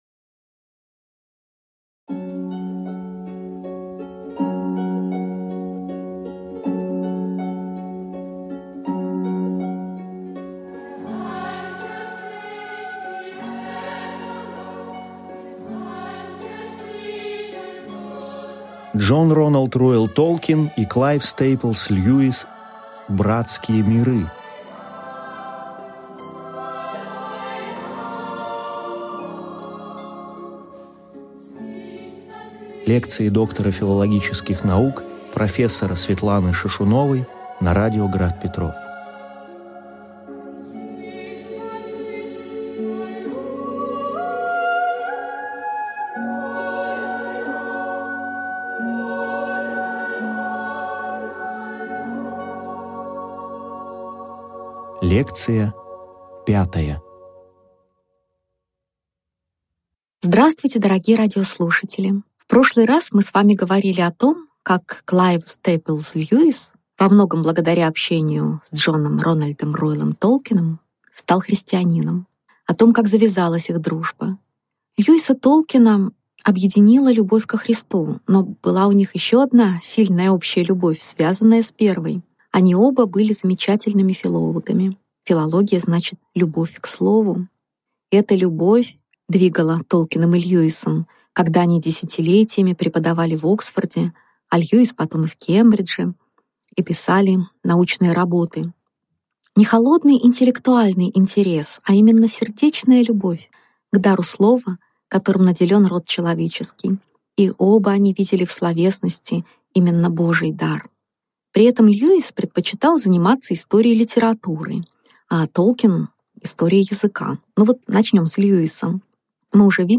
Аудиокнига Лекция 5. Дж.Р.Р.Толкин и К.С.Льюис как ученые | Библиотека аудиокниг